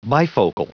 Prononciation du mot bifocal en anglais (fichier audio)
Prononciation du mot : bifocal